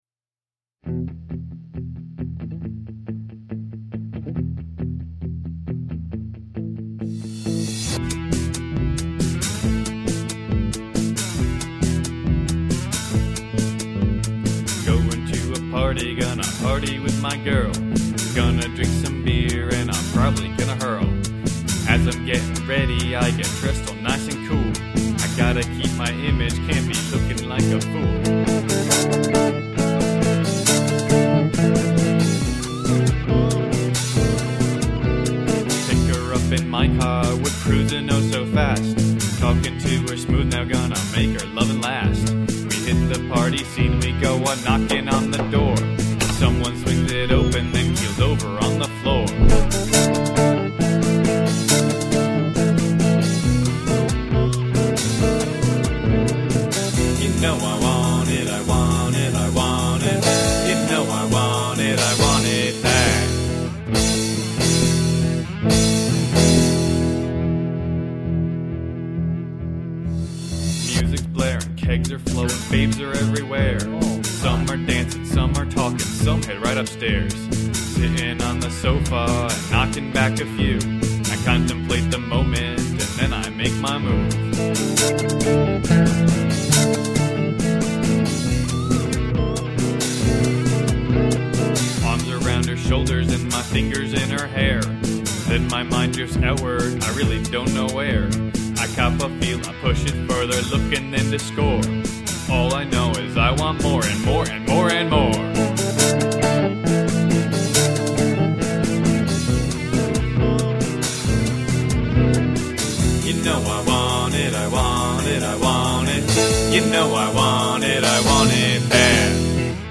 on lead guitar